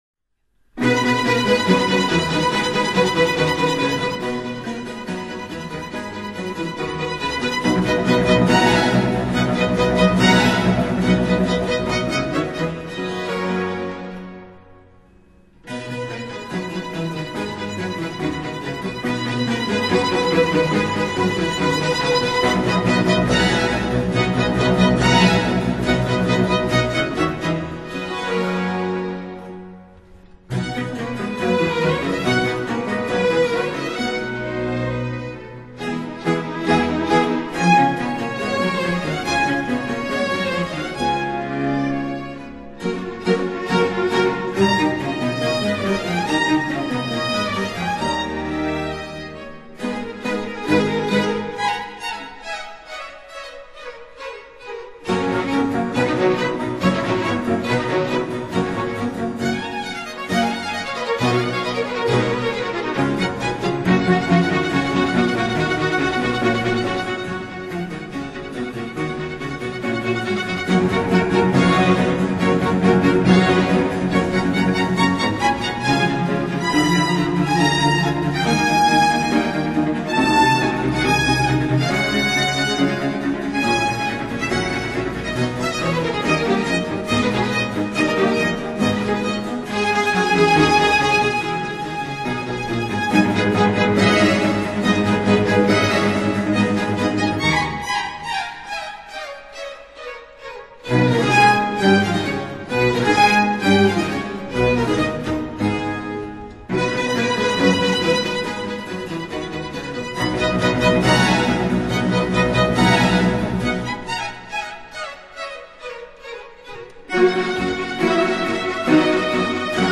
歌剧
版    本:阿姆斯特丹音乐厅演出实录
这部歌剧中的大多数音乐都轻快而活跃，节奏略快。
该歌剧灌录自现场演出，录音效果极棒，声效细致逼真，而且还免除了通常音乐会实录中不得不听到的观众掌声。